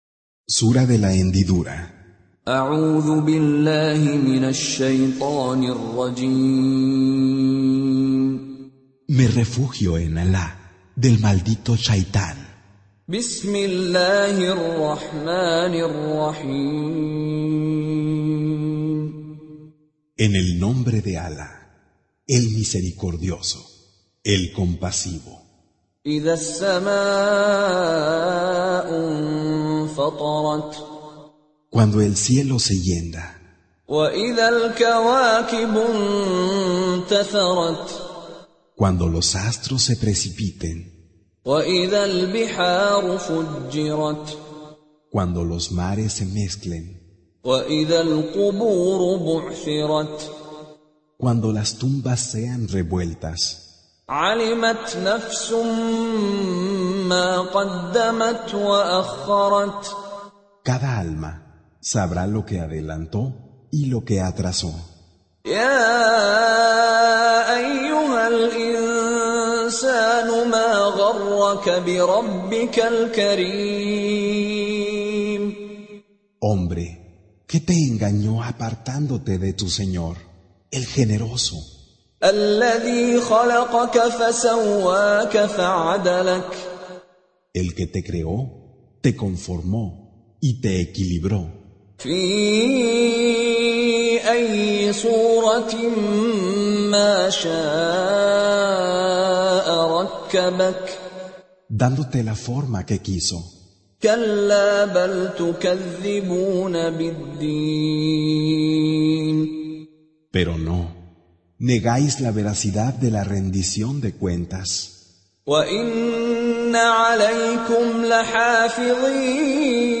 Spanish Transelated Recitation of Sheikh Meshary Rashed